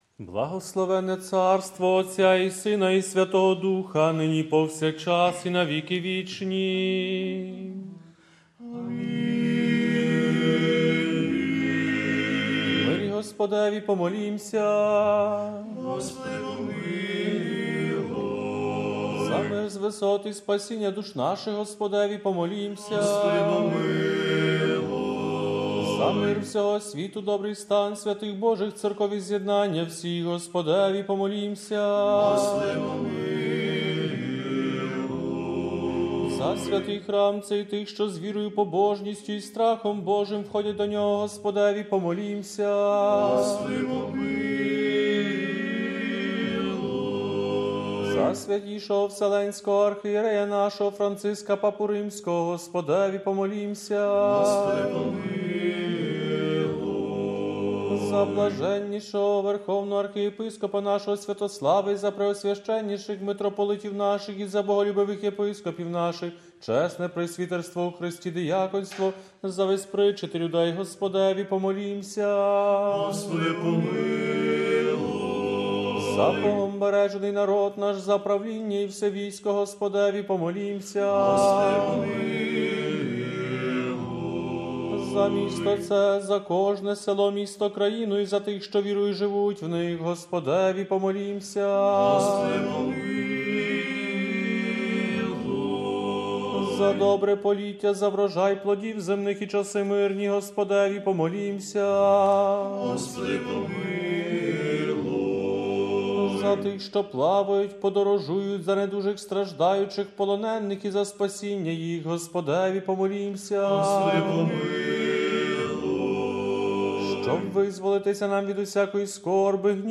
Співали студенти богослови Папської Української Колегії святого Йосафата.